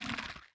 Minecraft Version Minecraft Version snapshot Latest Release | Latest Snapshot snapshot / assets / minecraft / sounds / block / sculk / spread5.ogg Compare With Compare With Latest Release | Latest Snapshot